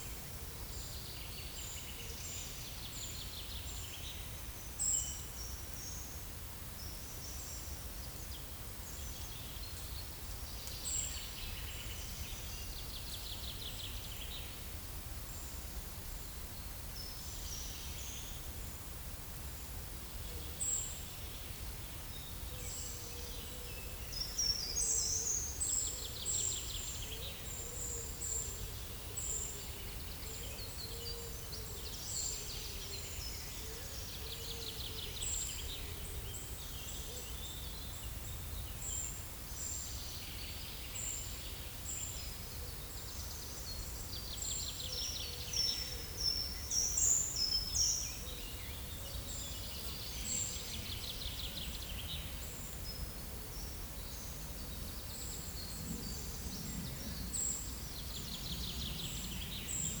Monitor PAM
Certhia brachydactyla
Certhia familiaris
Troglodytes troglodytes
Columba oenas
Columba palumbus